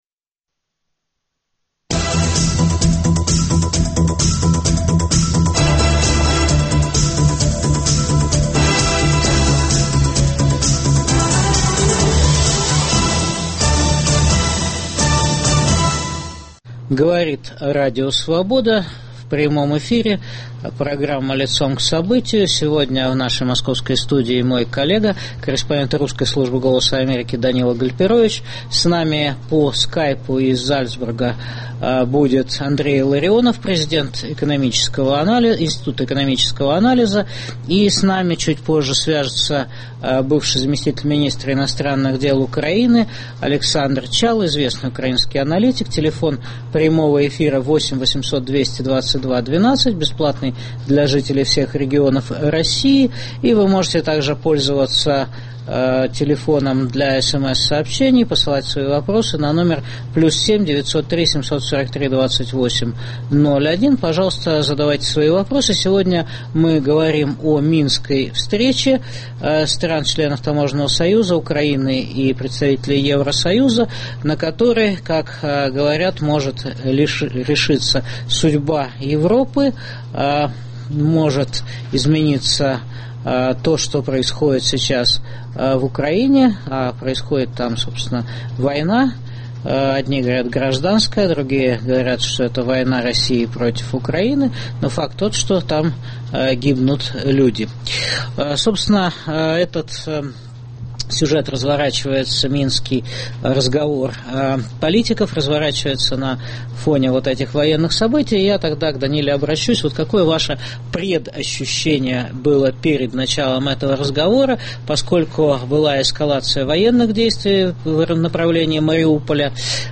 О встрече Путина и Порошенко, войне на Украине и пути к миру в Донбассе дискутируют президент Института экономического анализа Андрей Илларионов, бывший замминистра иностранных дел Украины Александр Чалый.